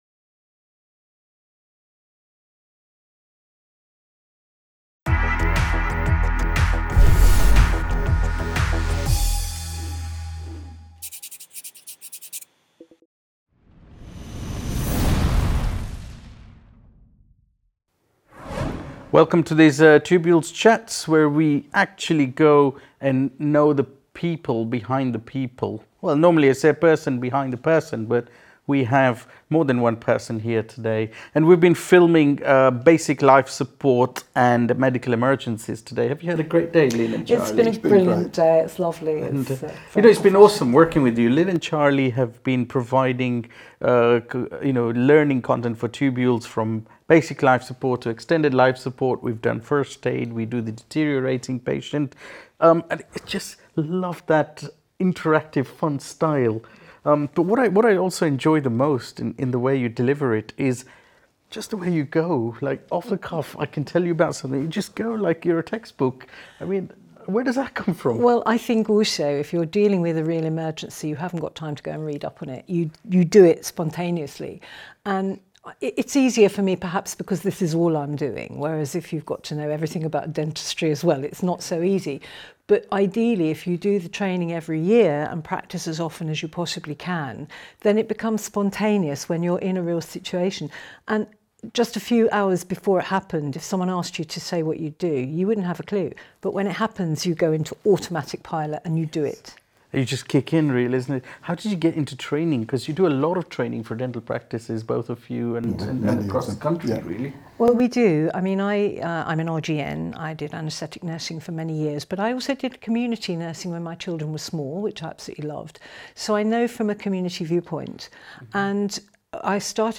Tubules Conversations Podcast